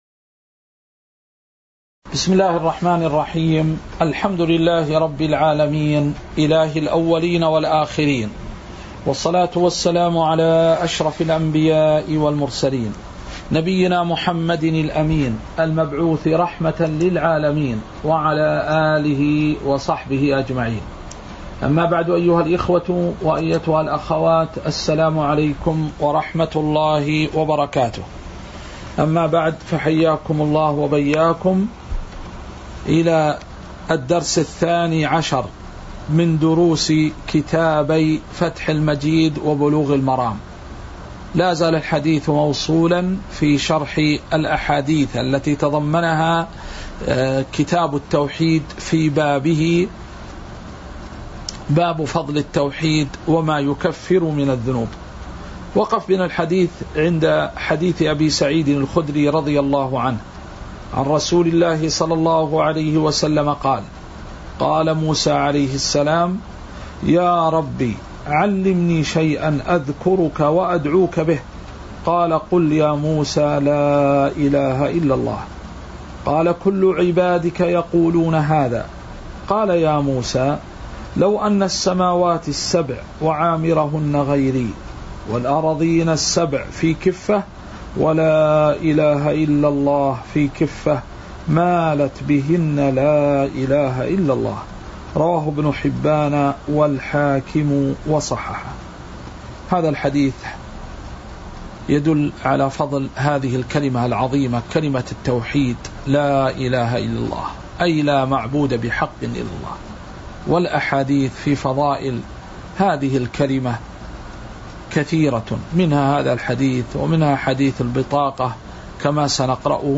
تاريخ النشر ٢ ذو القعدة ١٤٤٤ هـ المكان: المسجد النبوي الشيخ